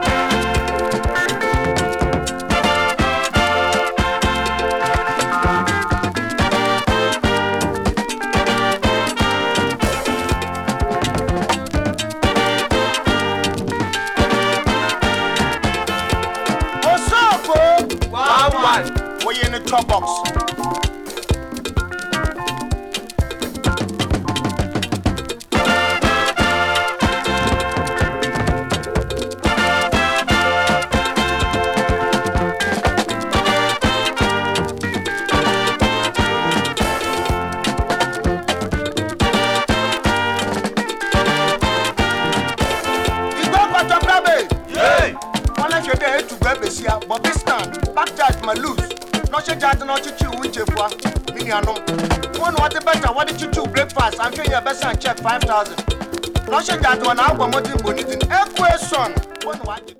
70s AFRO FUNKY 詳細を表示する